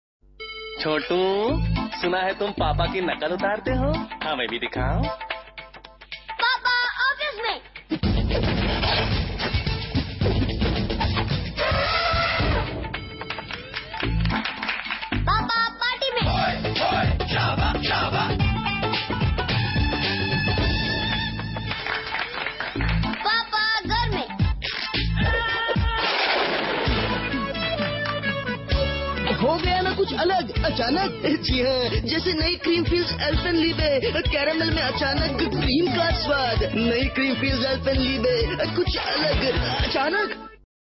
File Category : Free mobile ringtones > > Sms ringtones
File Type : Tv confectionery ads